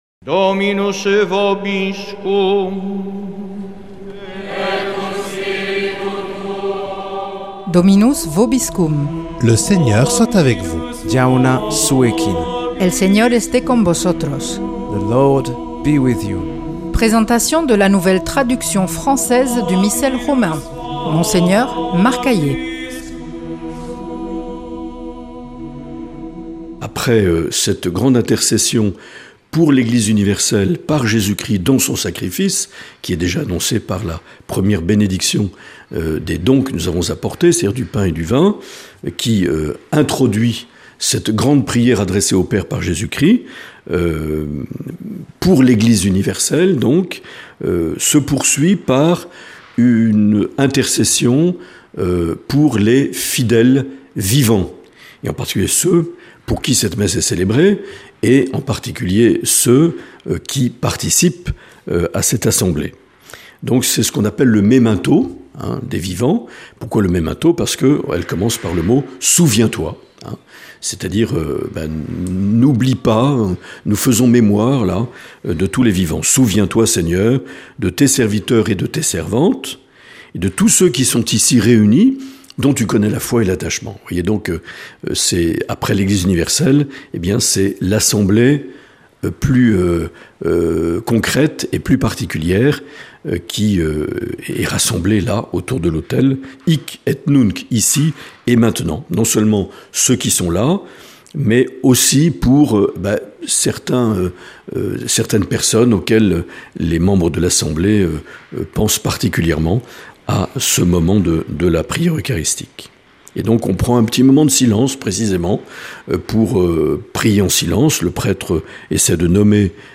Présentation de la nouvelle traduction française du Missel Romain par Mgr Marc Aillet
Présentateur(trice)